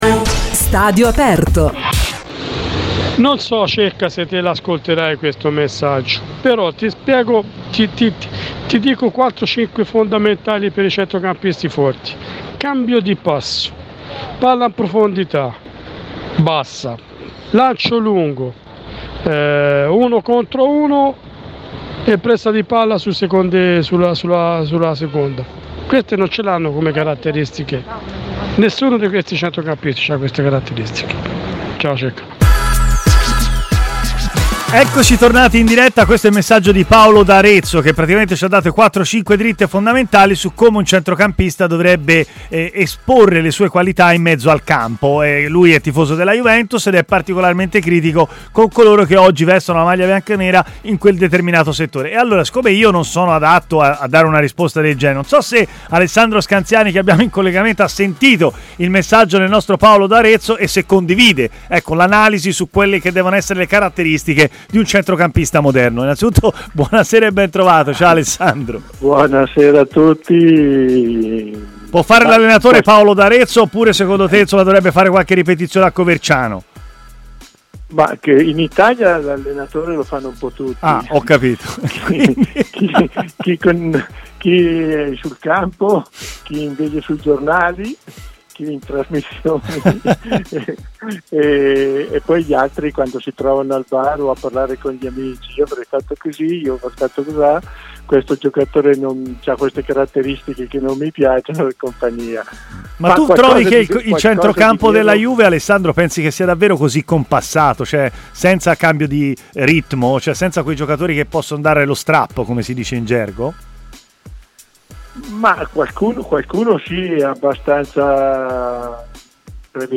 è intervenuto in diretta a Stadio Aperto, trasmissione di TMW Radio